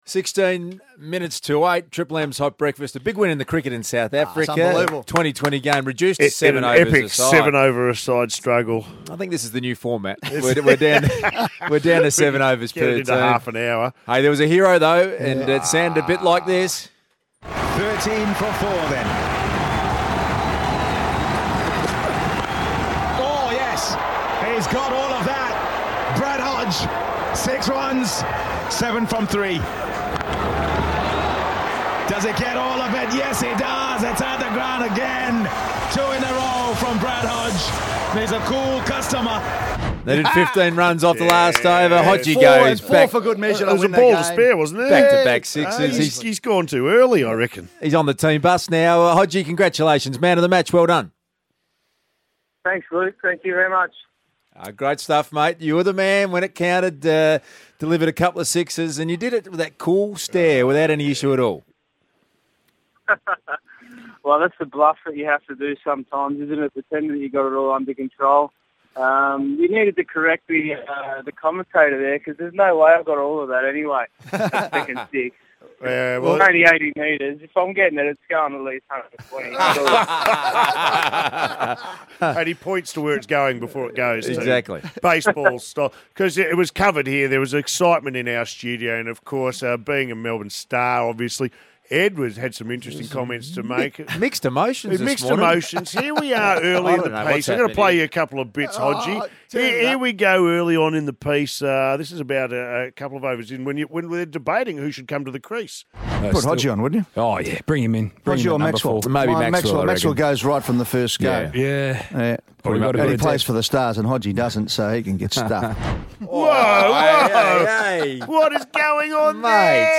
Brad Hodge Live From The Team Bus!